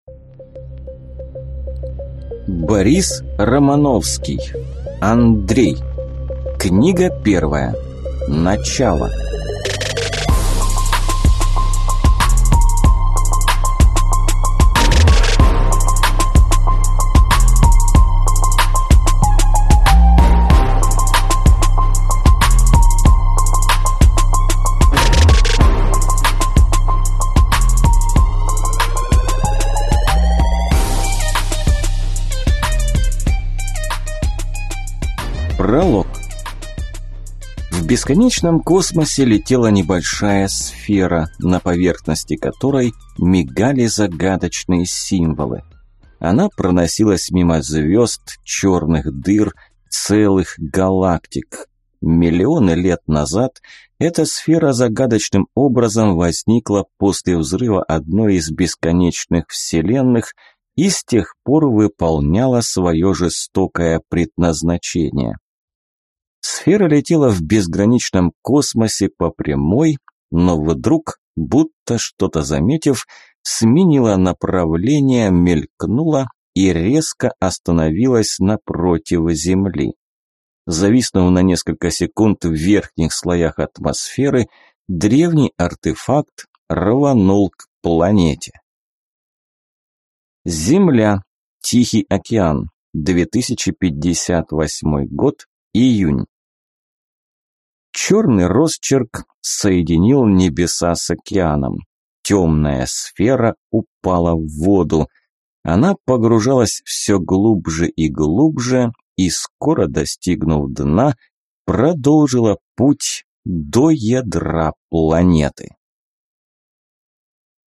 Аудиокнига Начало | Библиотека аудиокниг
Прослушать и бесплатно скачать фрагмент аудиокниги